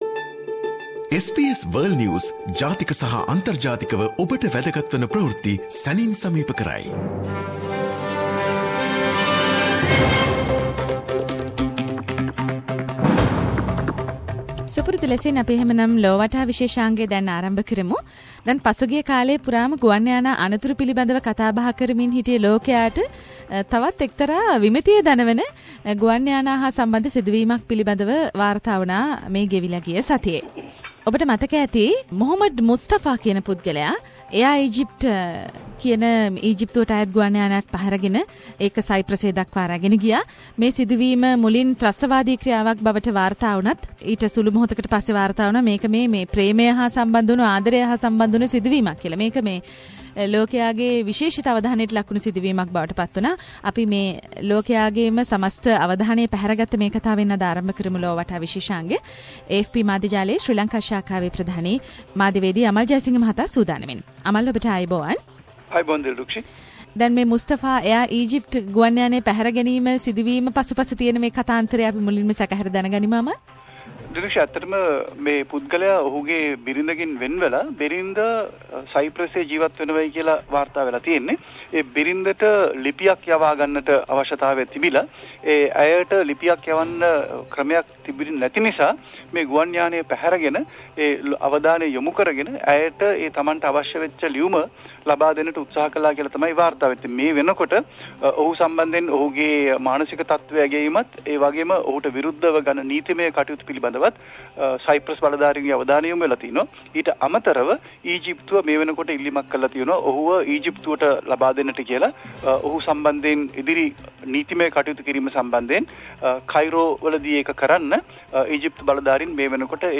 Weekly world news wrap